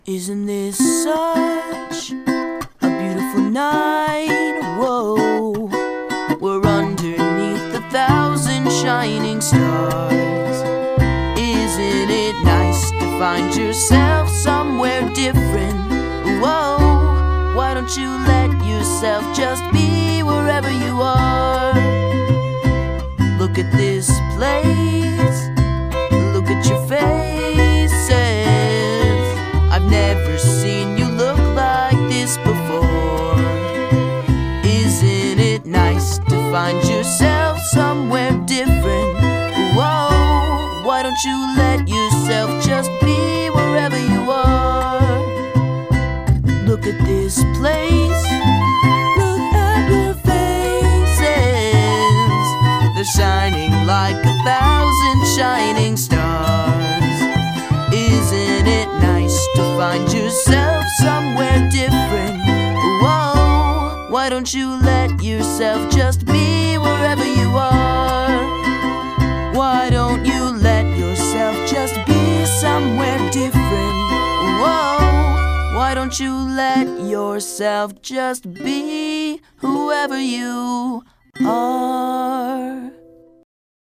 is soft, innocent, and loving at its core.